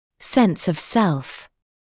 [sens ɔv self][сэнс ов сэлф]самоощущение; самосознание